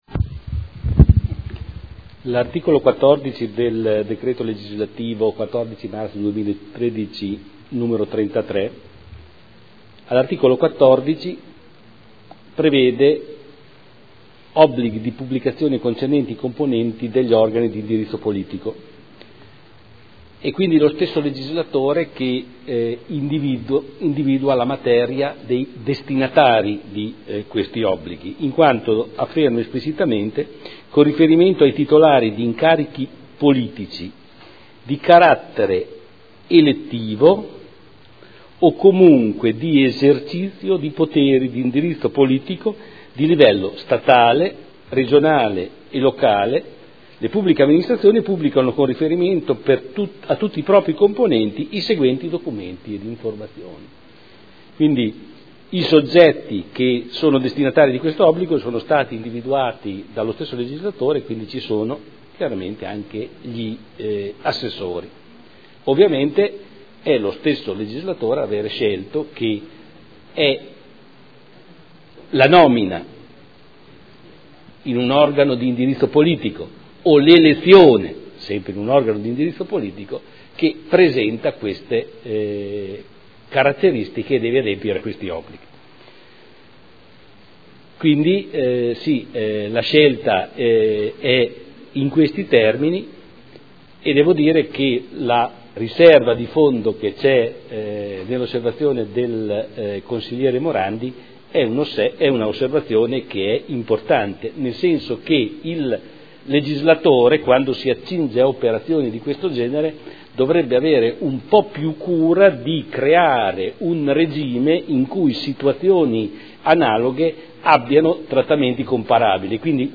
Giorgio Pighi — Sito Audio Consiglio Comunale